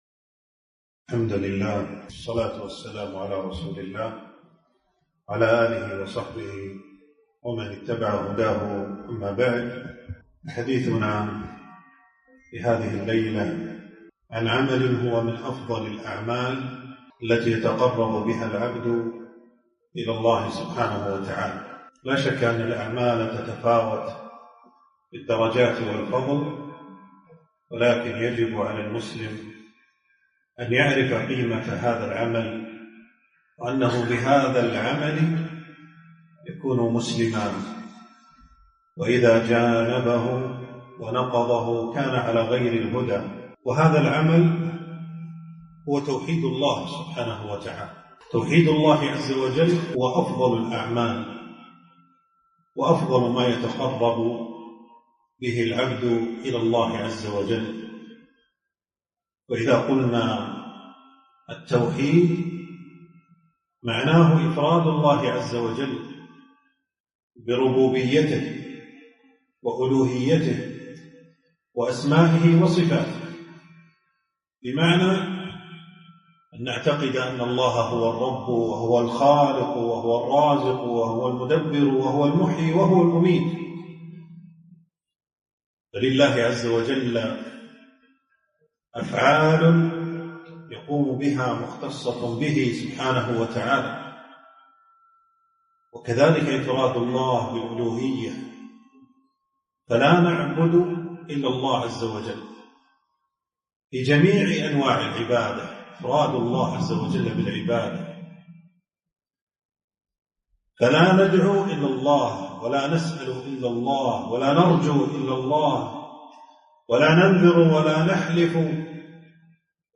محاضرة - فضل التوحيد